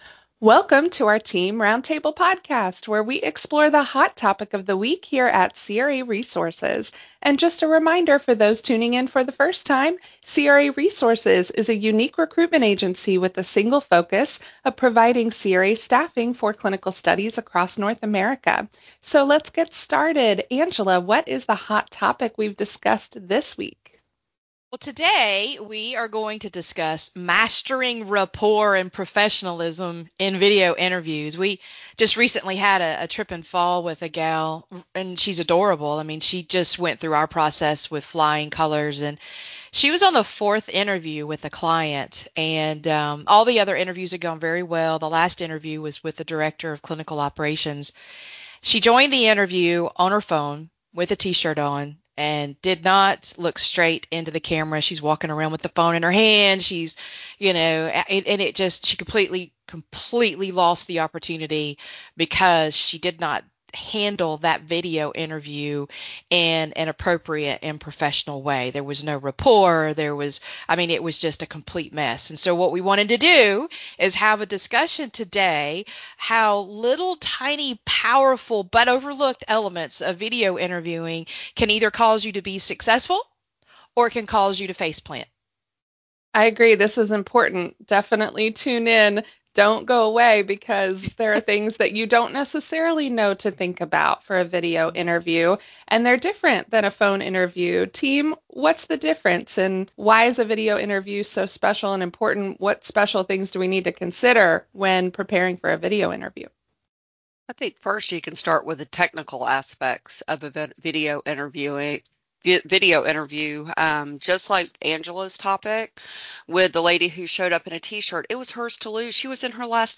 Today’s roundtable discussion is for everyone.
During this roundtable, the team explored these points through firsthand experience and candid stories from our years in hiring and candidate preparation.